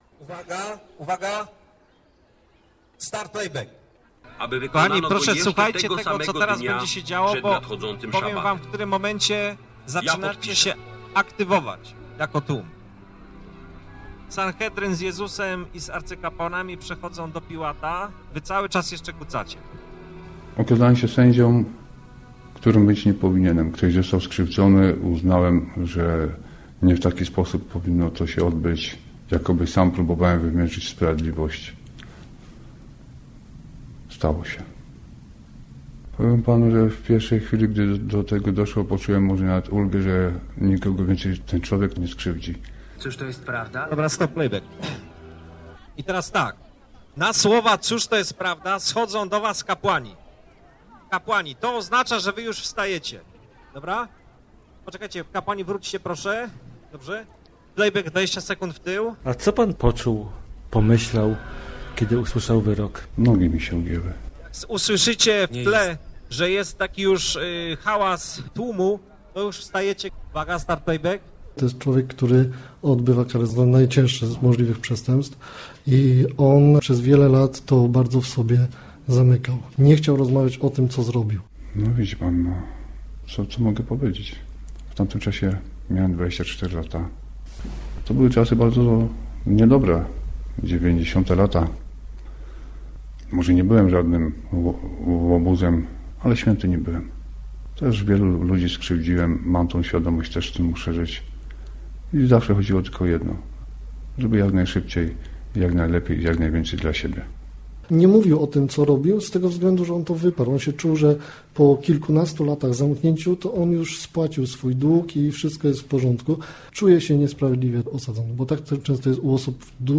Misterium mordercy - reportaż